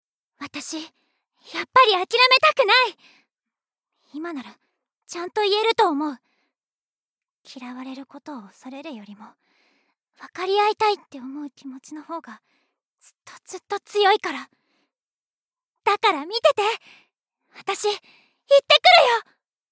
ボイスサンプル
まっすぐ